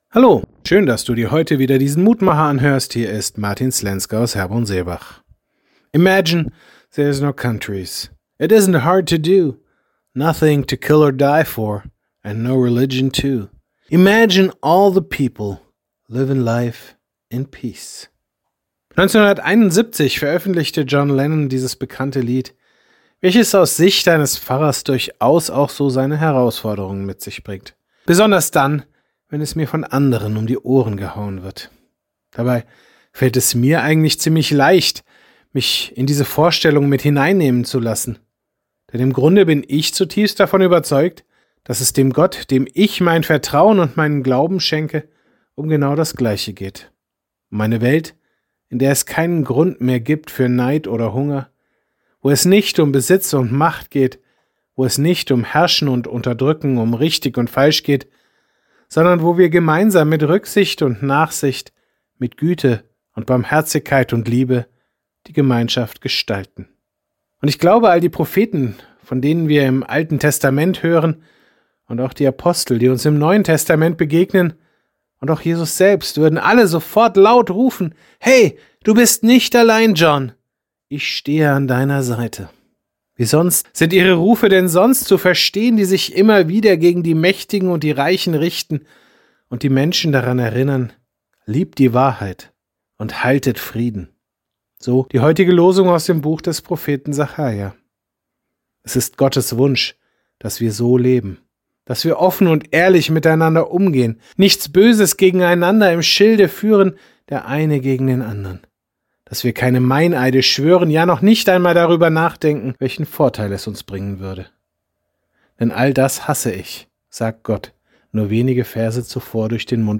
Mutmacher - Kleine Andacht zum Tag